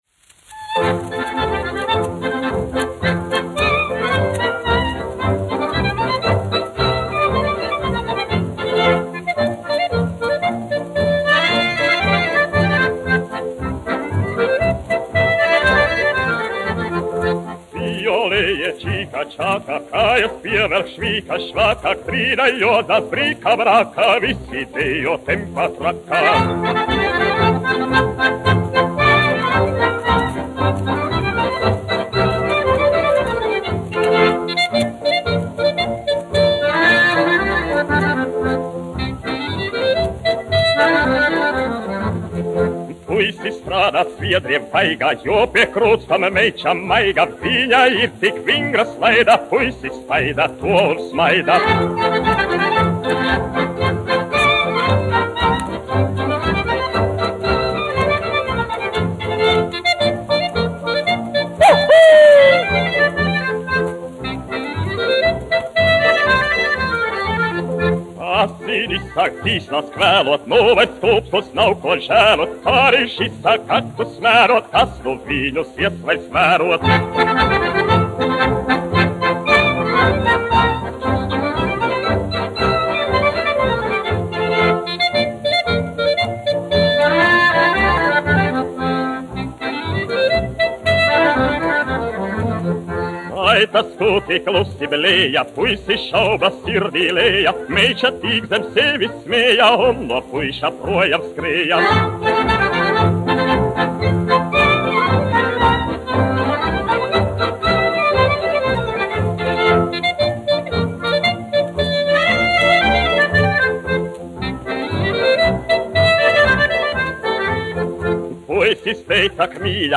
1 skpl. : analogs, 78 apgr/min, mono ; 25 cm
Polkas
Skaņuplate
Latvijas vēsturiskie šellaka skaņuplašu ieraksti (Kolekcija)